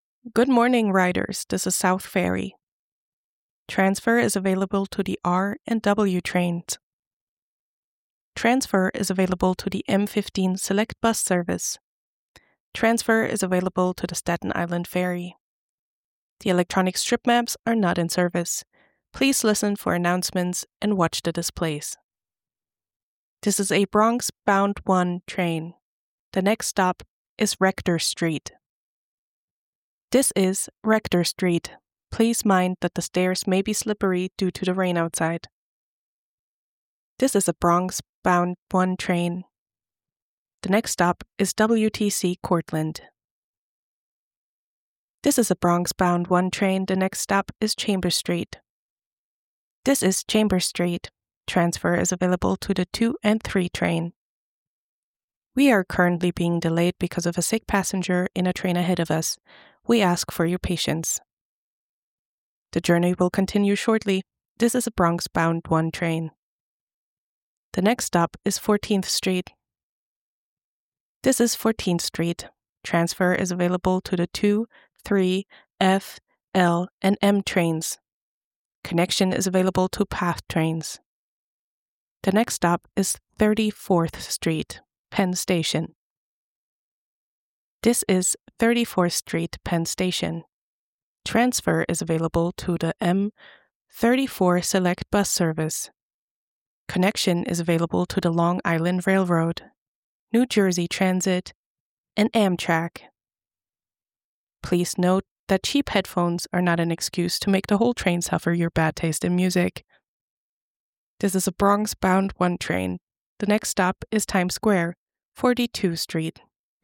You will hear (fictional) announcements from the New York subway, which have been closely edited together. These announcements follow similar structures so you may want to identify them first and then focus on the unique pieces of information while listening for a second time.